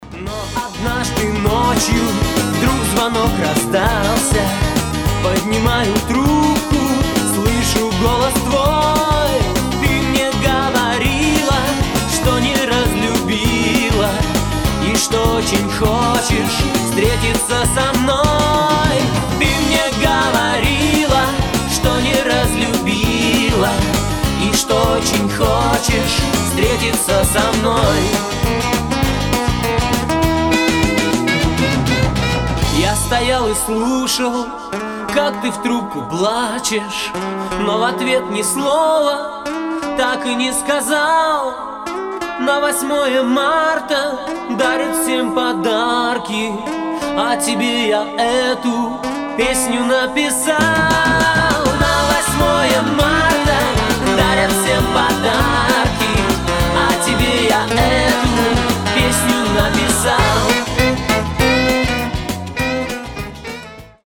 • Качество: 320, Stereo
грустные
эстрадные
русская эстрада